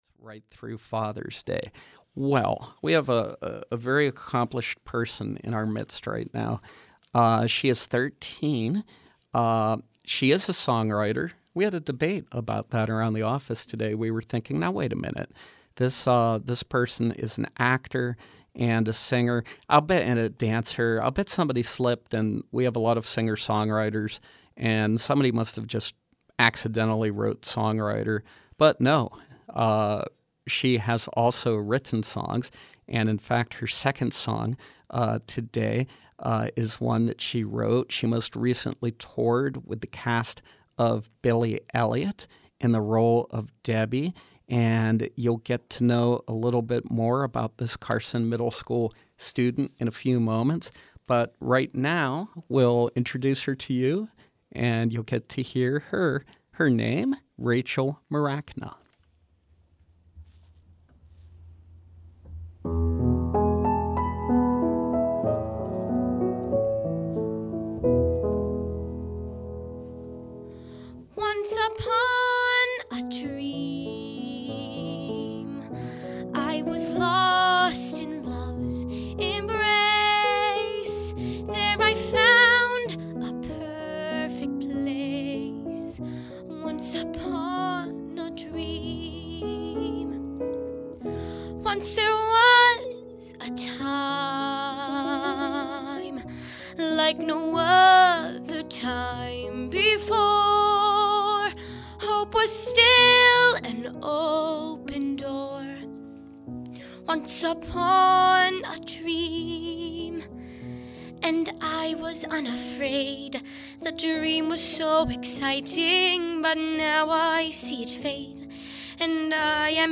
is a singer-songwriter and performer